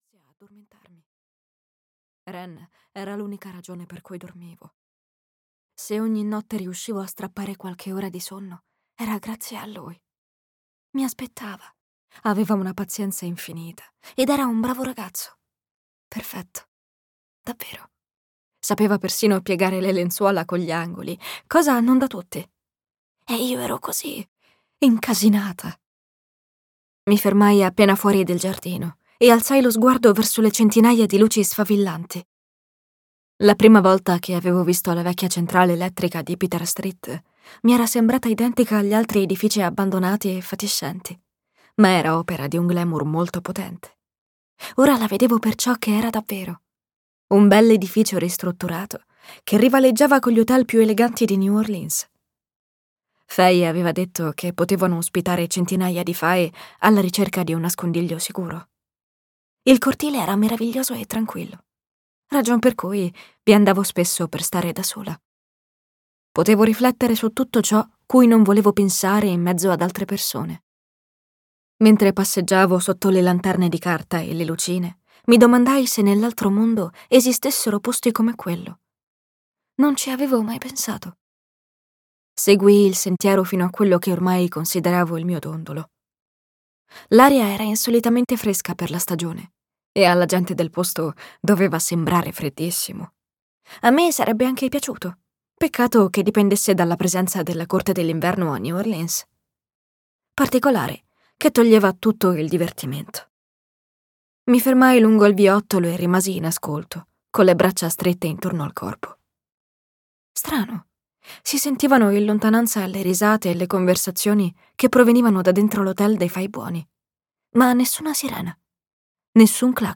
In fuga da te" di Jennifer L. Armentrout - Audiolibro digitale - AUDIOLIBRI LIQUIDI - Il Libraio